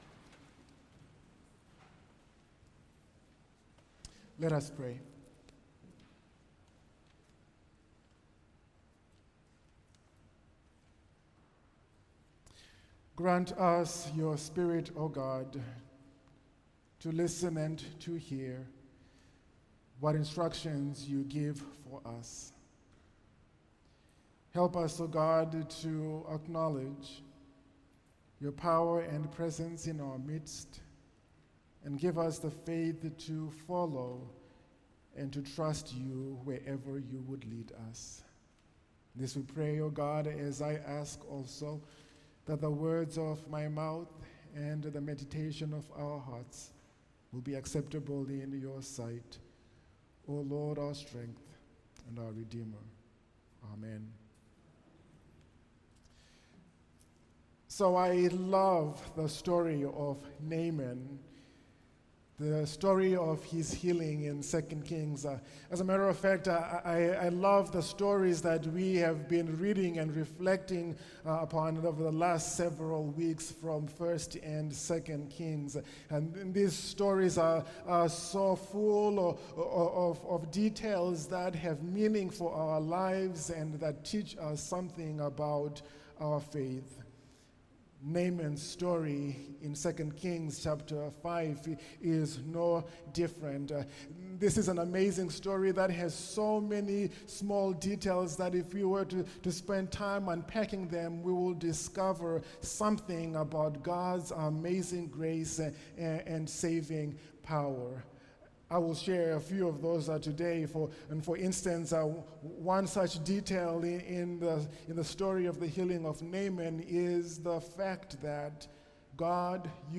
Trusting wihtout Reservation – Wesley United Methodist Church, Worcester, Massachusetts